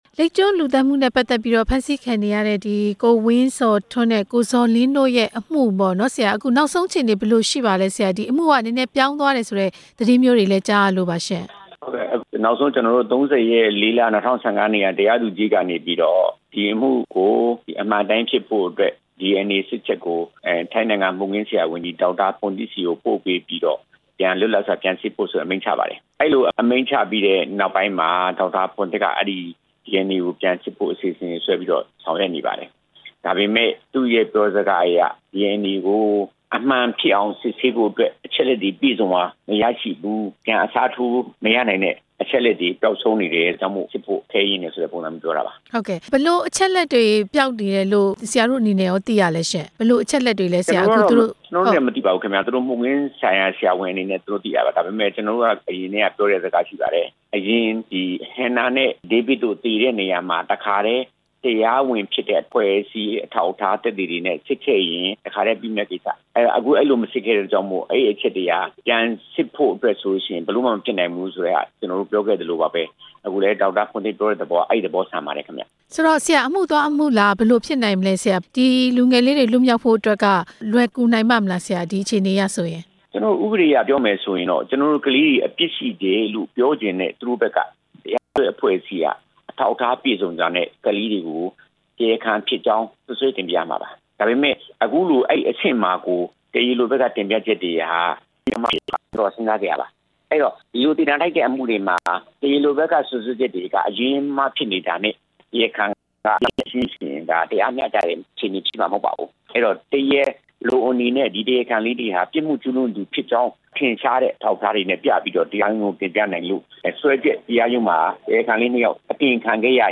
လိပ်ကျွန်းလူသတ်မှုမှာ DNA တချို့ ပျောက်ဆုံးနေတဲ့ အကြောင်းမေးမြန်းချက်